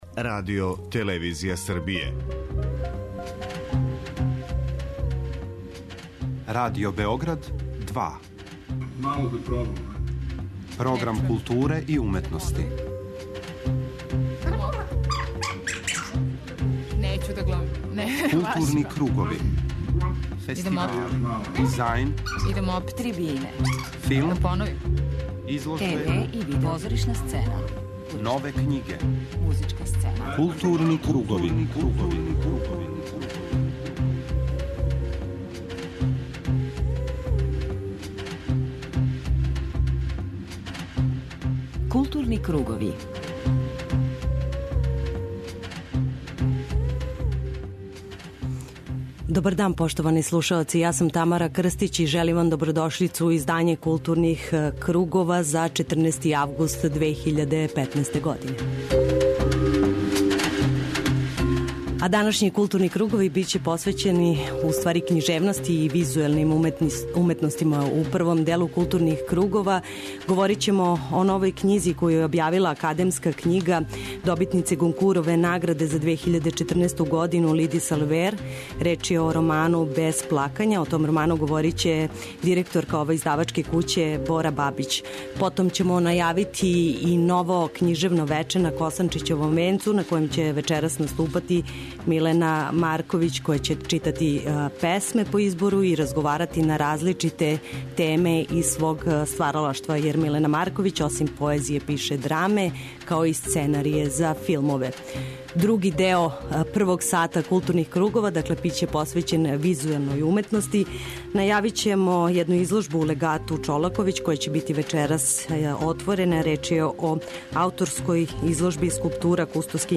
У темату Гутенбергов одговор слушаћете песничку репортажу из Старе Пазове у којој ће се смењивати поезија и разговори са учесницима